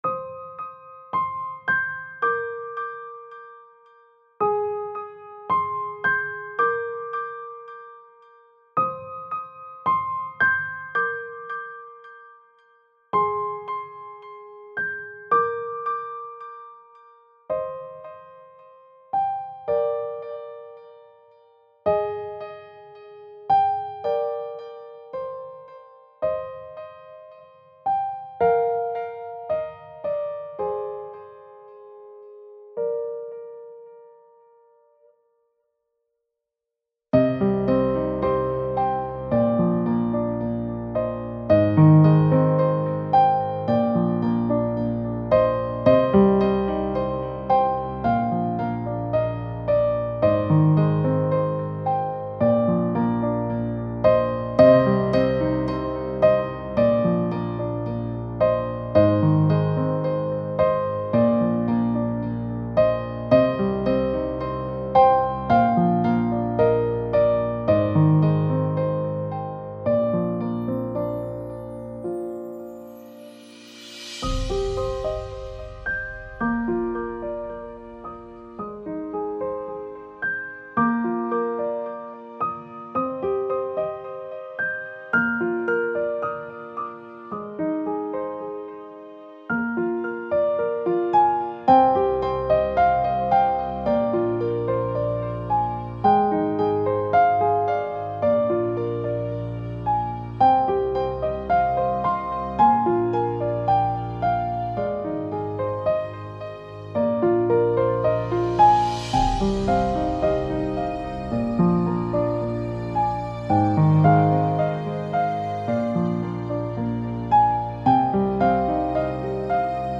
موزیک بی کلام پیانو